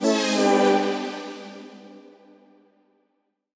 Index of /musicradar/future-rave-samples/Poly Chord Hits/Ramp Down
FR_ZString[dwn]-G.wav